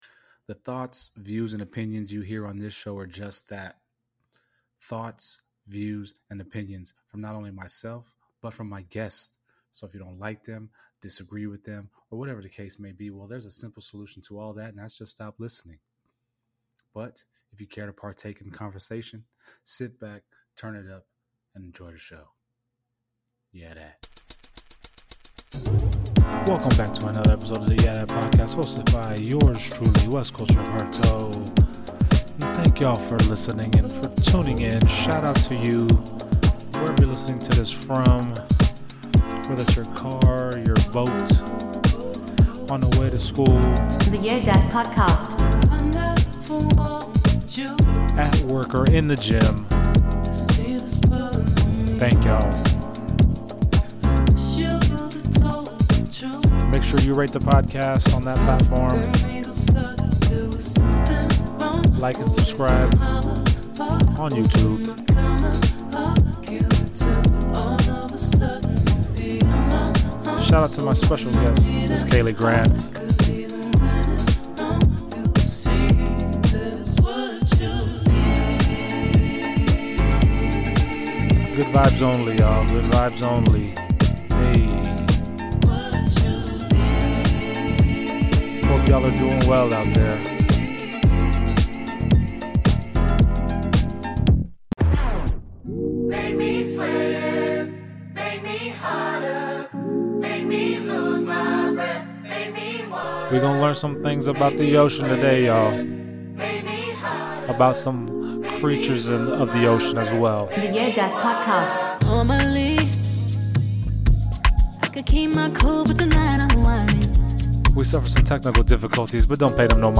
Tune is as they battled through technical difficulties to shed some light on a world a lot of people are afraid of!